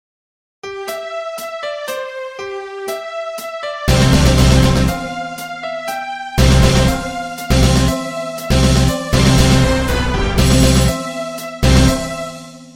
ringtone3